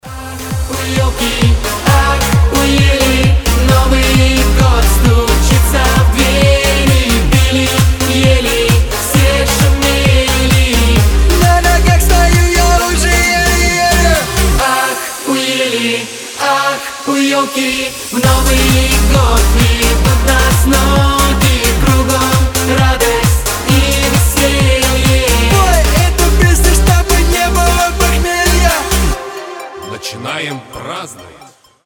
• Качество: 320, Stereo
позитивные
зажигательные
веселые